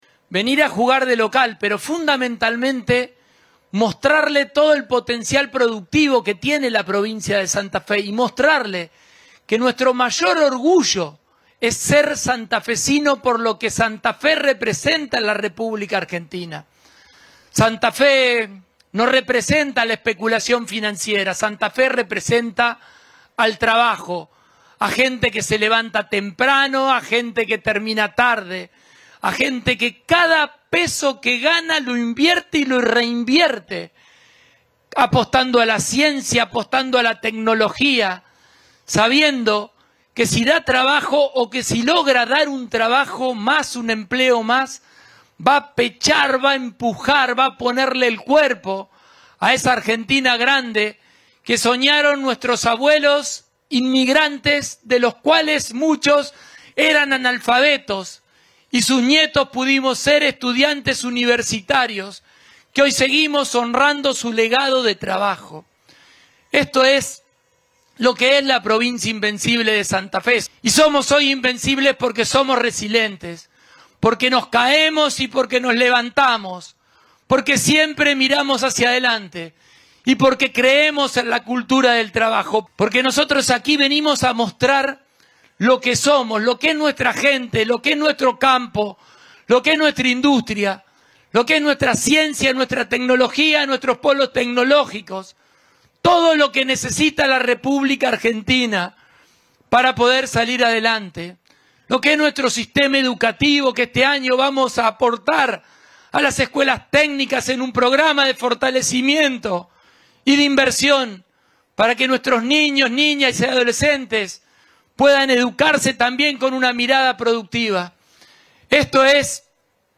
“Se puede administrar de manera eficiente el Estado y bajar impuestos como nunca se habían bajado en la provincia en los últimos 20 años”, afirmó el mandatario durante su intervención en el acto inaugural.
Durante la jornada también habló el ministro de Desarrollo Productivo, Gustavo Puccini, quien destacó la importancia de Expoagro como espacio de vinculación entre empresas, productores y gobiernos.